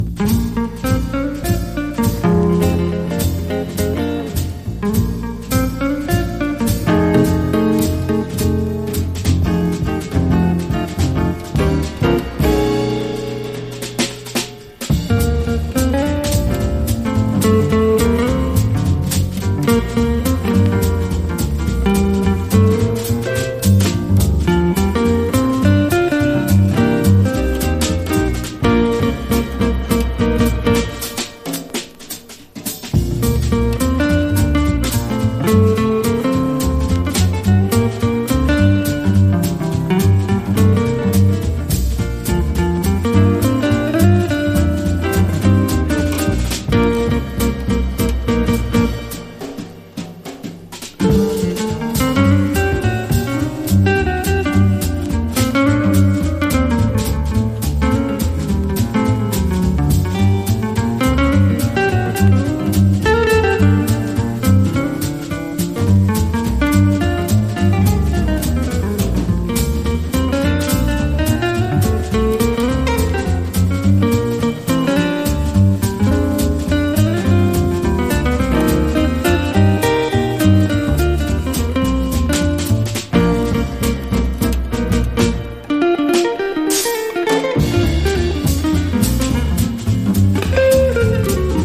和ジャズ・ギターのトップ二人による1967年の共演盤！